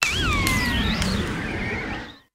Grito de Bramblin.ogg
Grito_de_Bramblin.ogg